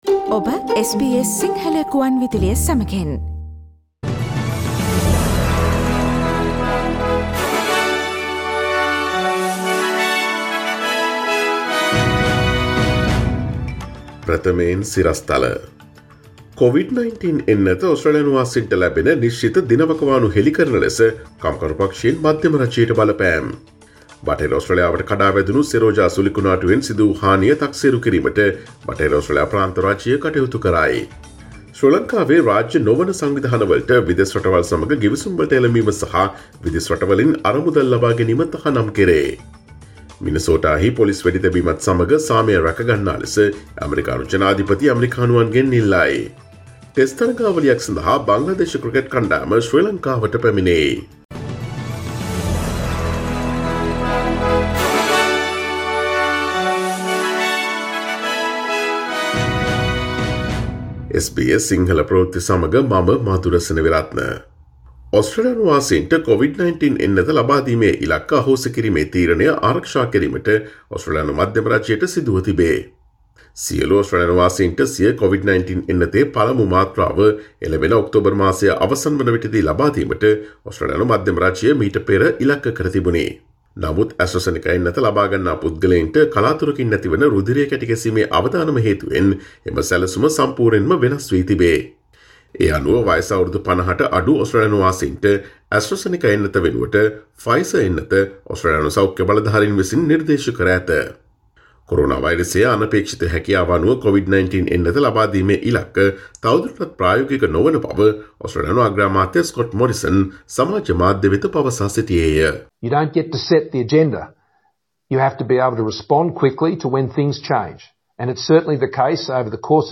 Here are the most prominent Australian,Sri Lankan, International, and Sports news highlights from SBS Sinhala radio daily news bulletin on Tuesday 13 April 2021.